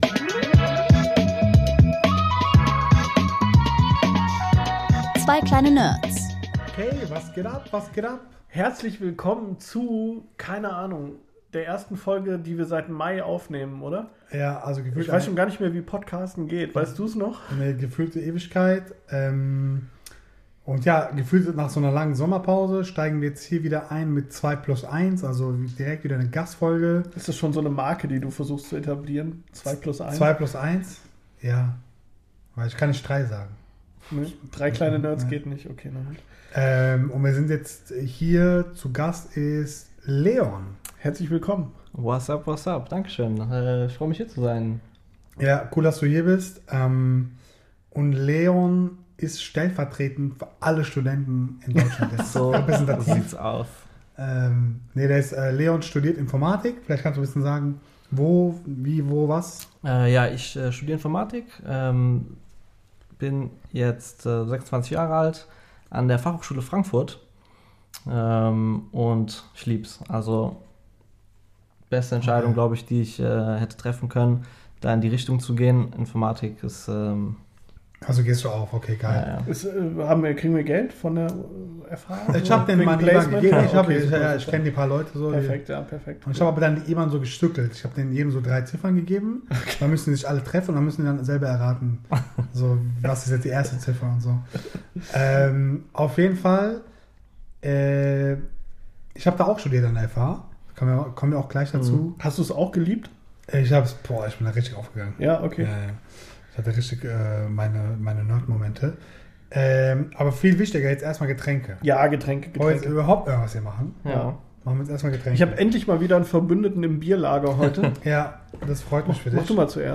Eine lockere Folge, in der wir einfach mal reinschauen, wie es ist, 2025 Informatik zu studieren – ehrlich, nahbar und mit jeder Menge Studentenvibes.